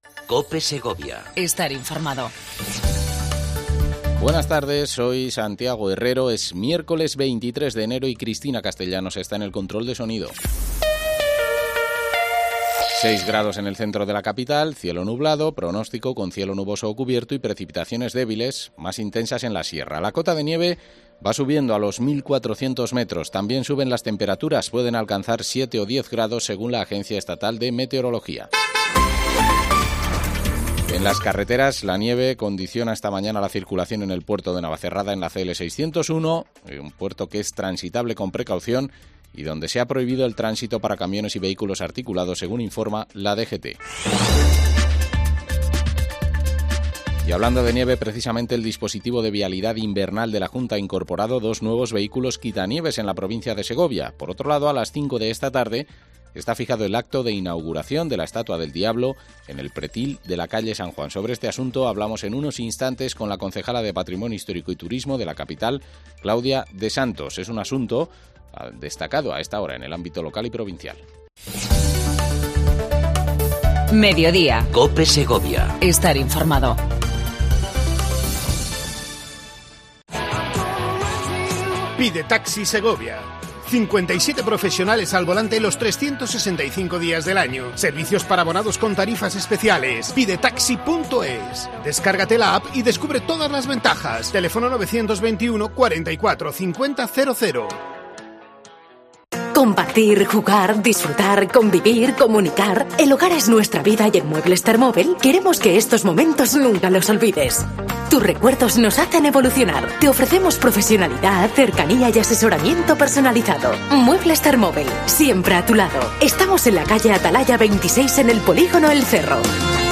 Entrevista a Claudia de Santos, Concejala de Patrimonio Histórico y Turismo de Segovia. Inauguración de la estatua del diablo de la calle San Juan.